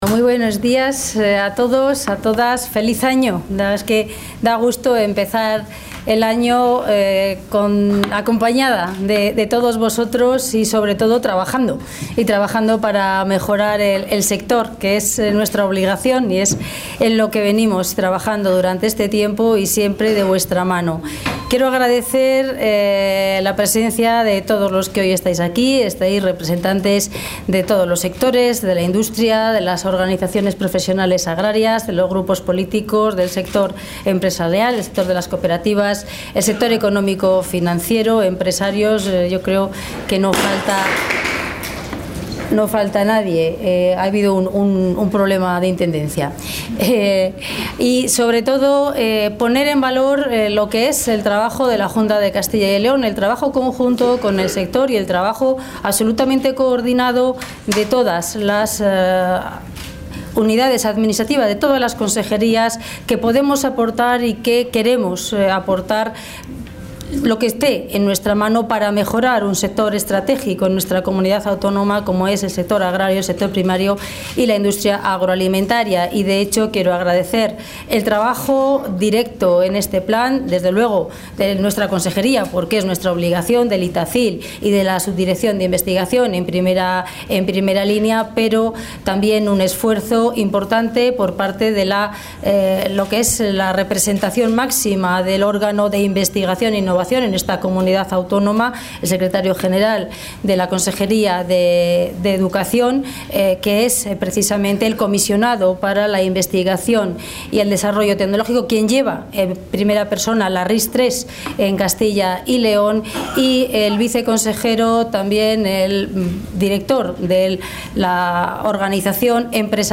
La consejera de Agricultura y Ganadería, Milagros Marcos, ha presentado esta mañana un Plan de Impulso a la Bioeconomía...